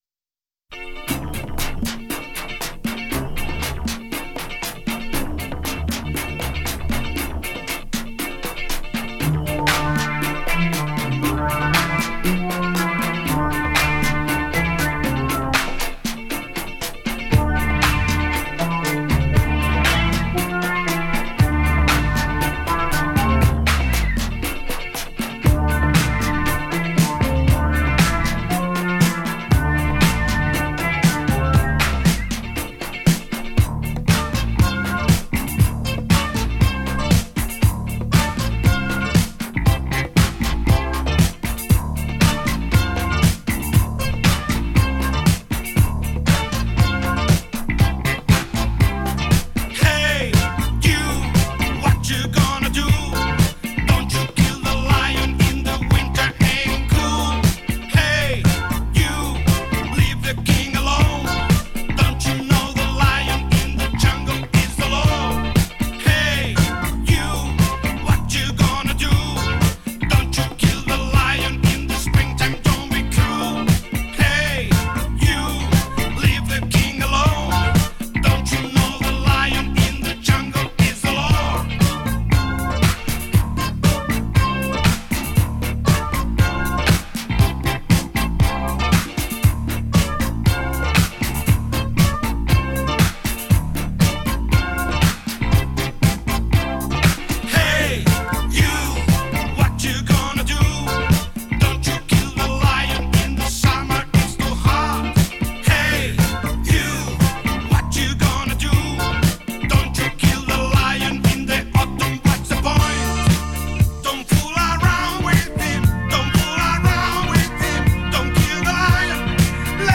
играющая в стиле R & B и Funk Rock